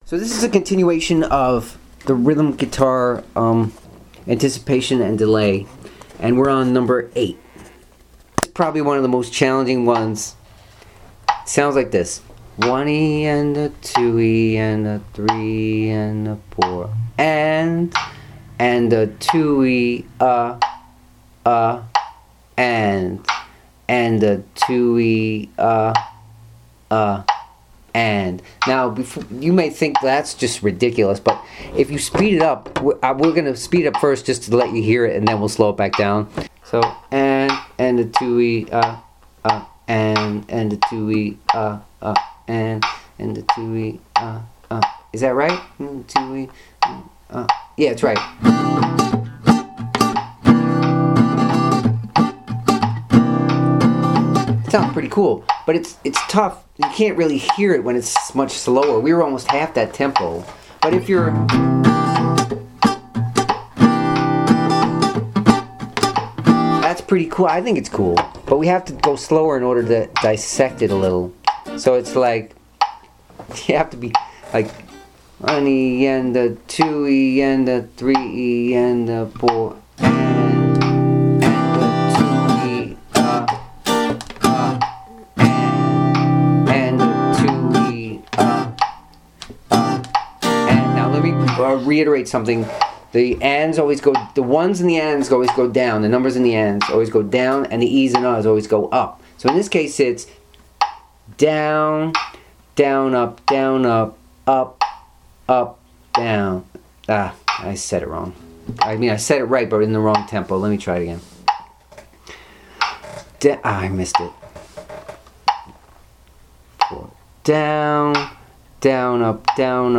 8-anticipated-strumming.mp3